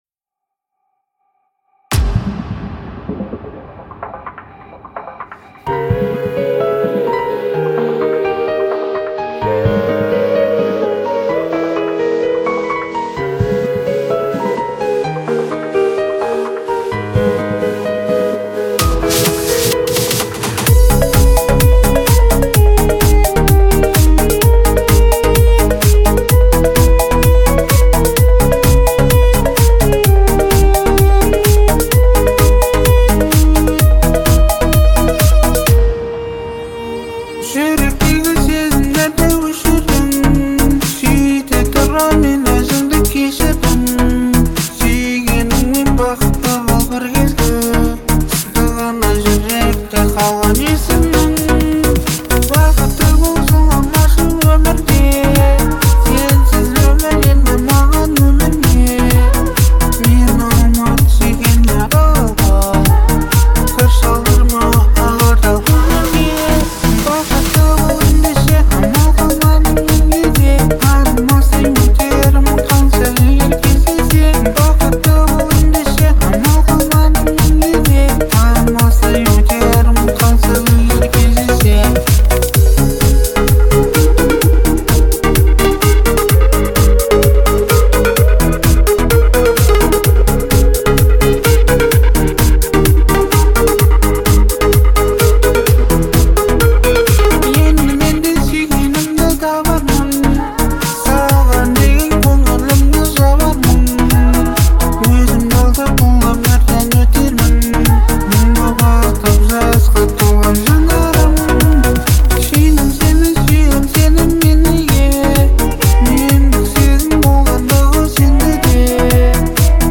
отличается мелодичностью и запоминающимися мотивами